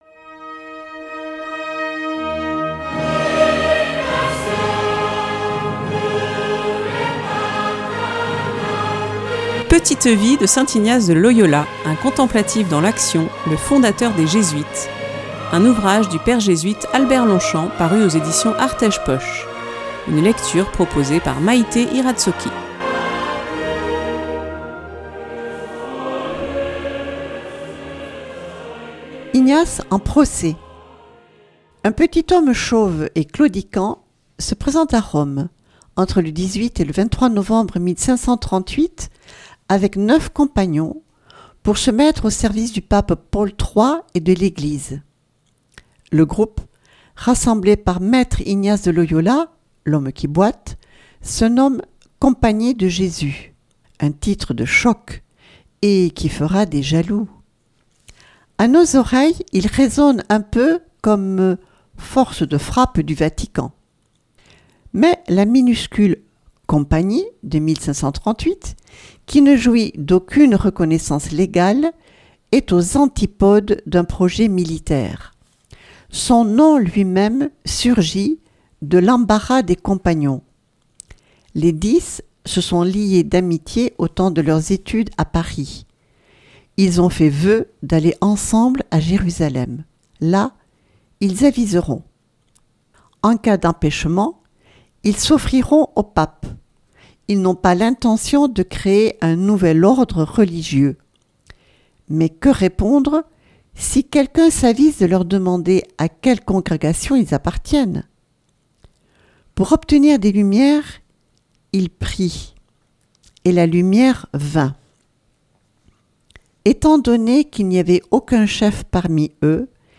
Une lecture